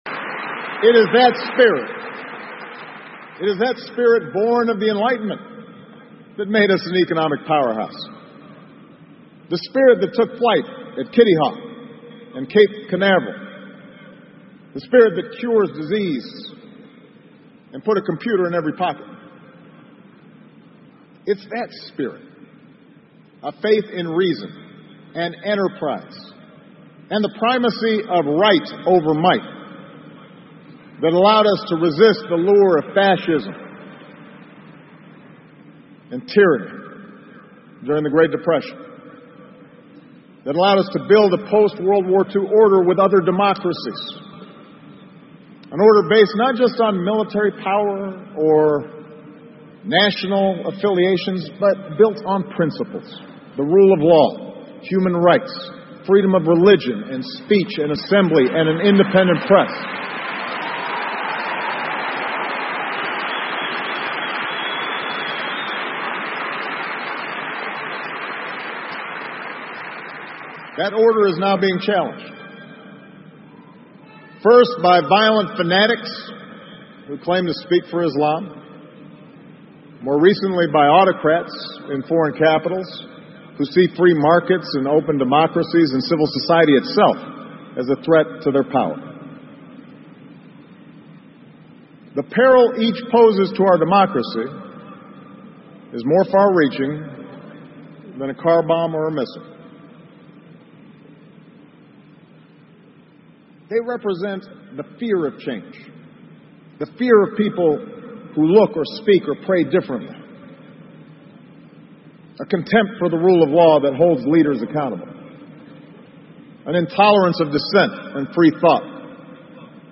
奥巴马每周电视讲话：美国总统奥巴马告别演讲(13) 听力文件下载—在线英语听力室